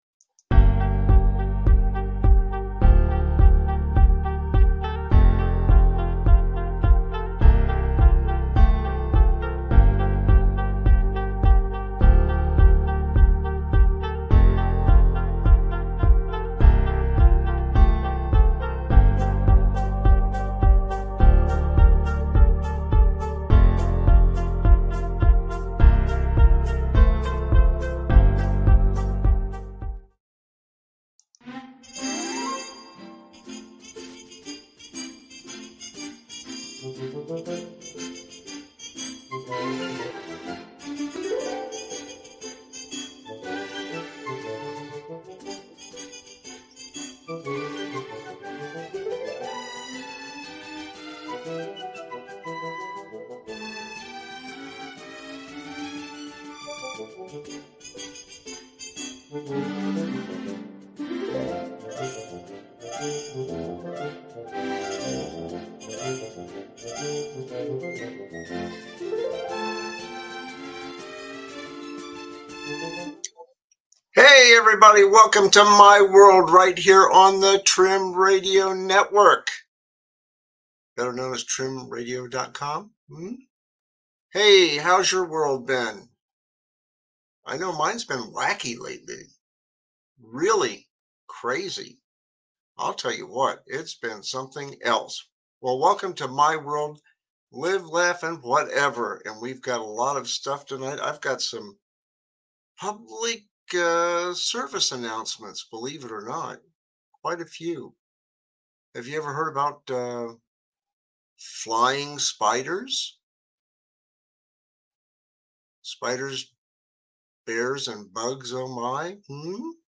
My WorldLive, Laff, Whatever is a satirical talk show that tackles the absurdities of life with ahealthy dose of humor.
No topic is off-limits, and his guests, arotating cast of comedians, commentators, and everyday folks, add their own uniqueperspectives to the mix.